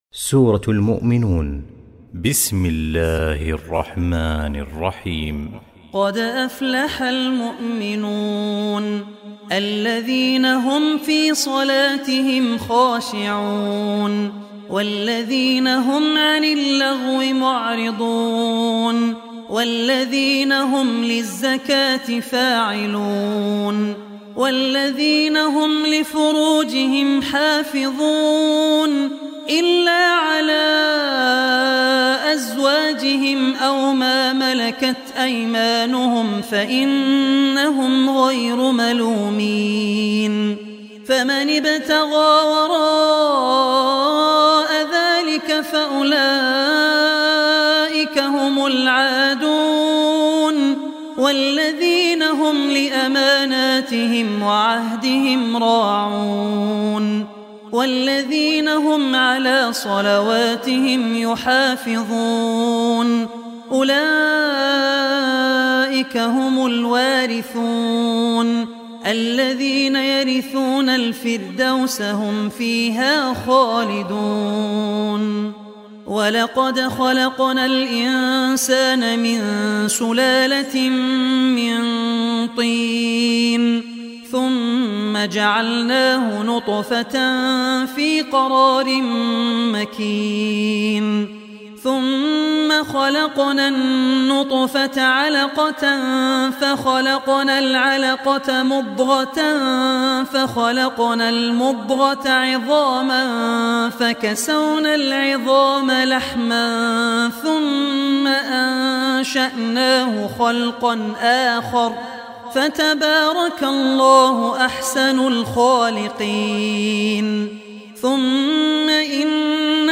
Surah Muminun Recitation by Abdur Rehman Al Ossi
Surah Muminun is 23rd chapter of Holy Quran. Listen online mp3 tilawat / recitation of Surah Muminun in the voice of Abdur Rehman Al Ossi.